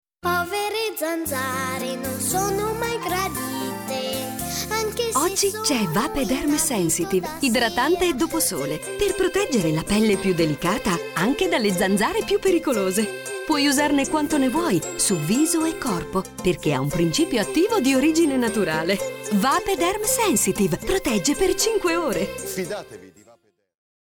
Sprechprobe: Werbung (Muttersprache):
Very adaptable voice, young, warm, pleasant, sexy, professional...